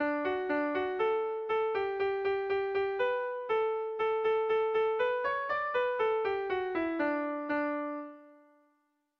Dantzakoa
ABD